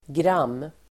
Ladda ner uttalet
Uttal: [gram:]